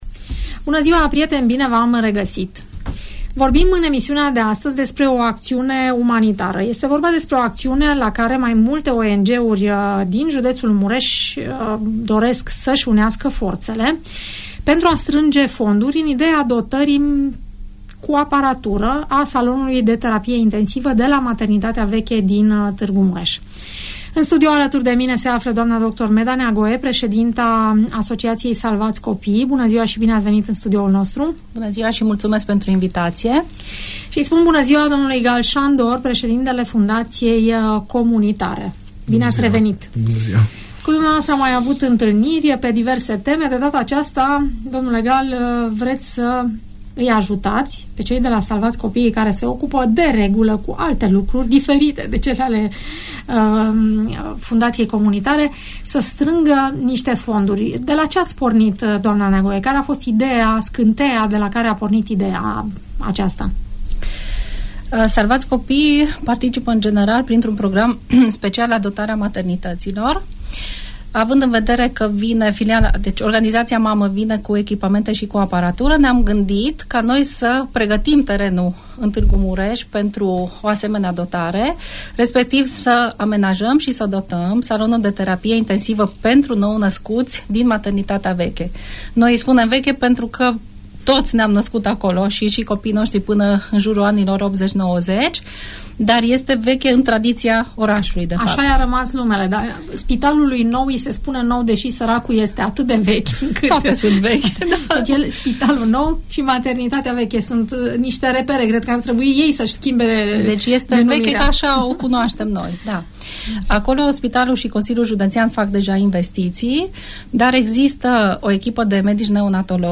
învitați la Radio Tg Mureș